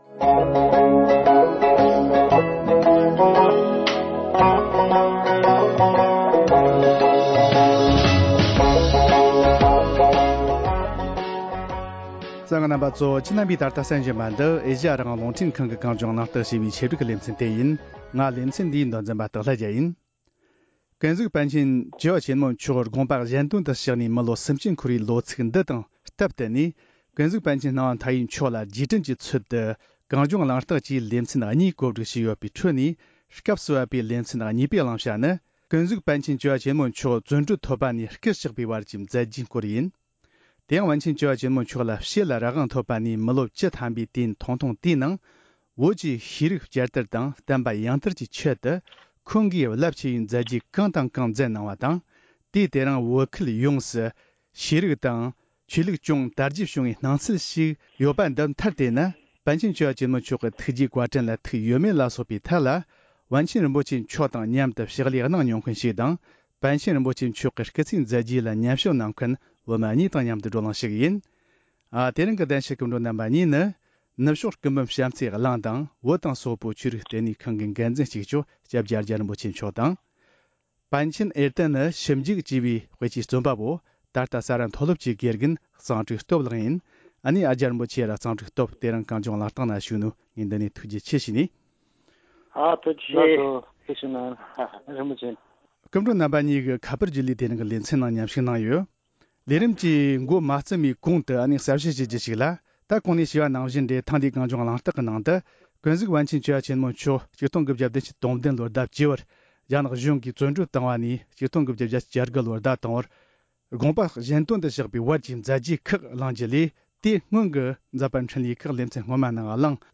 དེ་རིང་གི་གདན་ཞུ་སྐུ་མགྲོན་གཉིས་ནི།